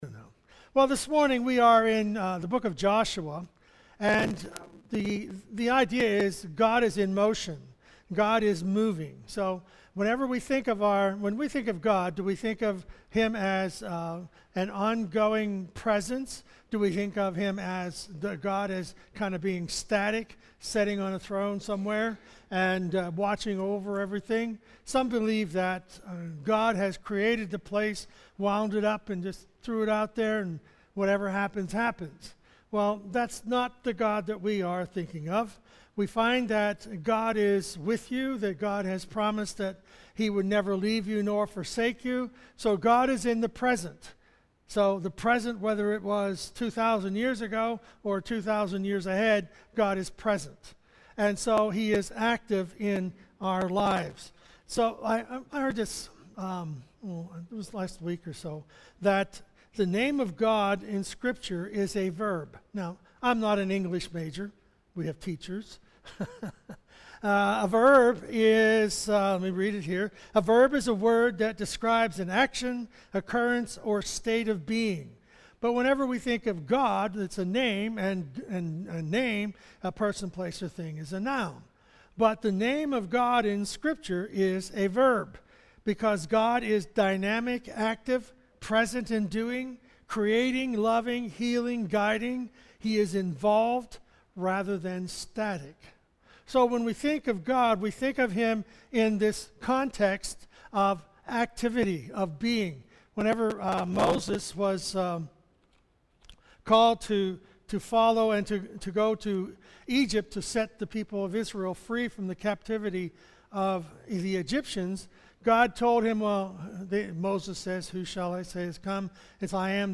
First Assembly of God, Windber, PA, Sermons is a podcast of sermons given each Sunday. Each week we dive into any subject in the bible in order to help teach each other more about our lord and savior Jesus Christ.